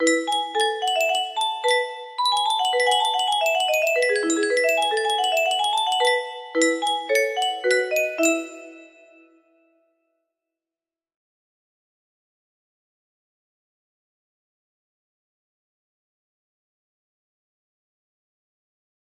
Bach Gavotter en Rondeaux music box melody
Yay! It looks like this melody can be played offline on a 30 note paper strip music box!